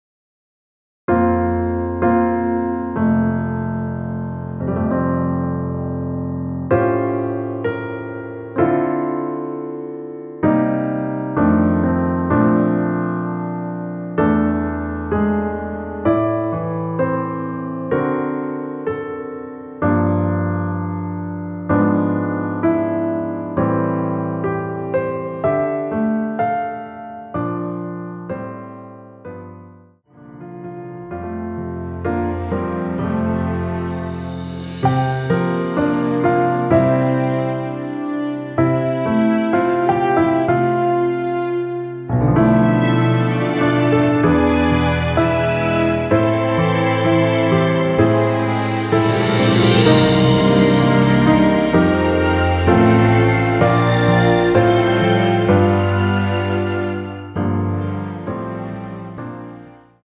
전주 없이 시작하는 곡이라 노래하기 편하게 전주 1마디 만들어 놓았습니다.(미리듣기 확인)
원키에서(-1)내린 MR입니다.
앞부분30초, 뒷부분30초씩 편집해서 올려 드리고 있습니다.